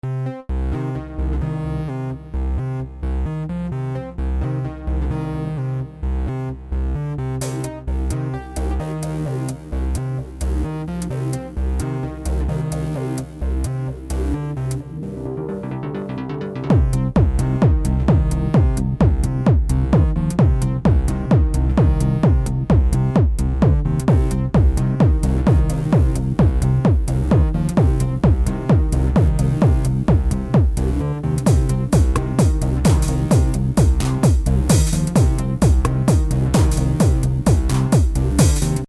However, I found some 20minutes and created a small trance track. Nothing special, not the melody, nor the patches. Just usual 2001 trance style, but well, I always enjoy working on music, and I might continue this track someday, it could have some potential.